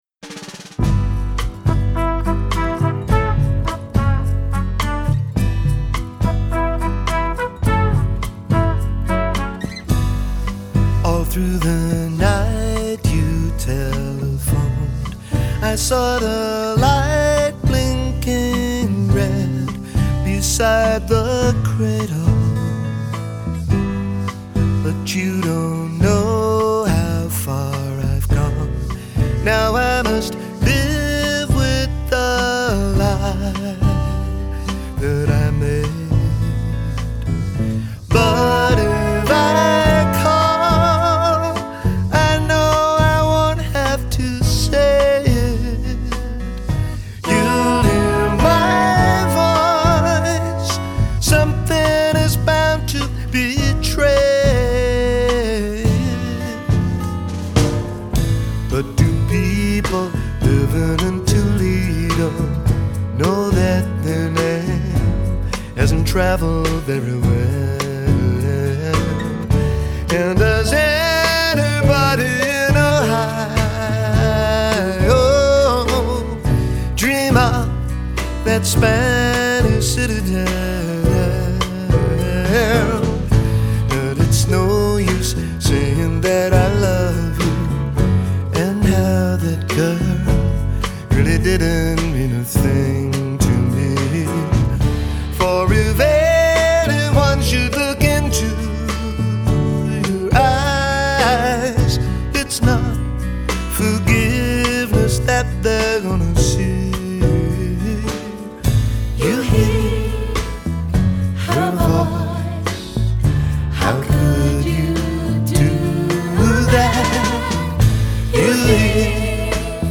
I chalk that up to its supremely catchy chorus.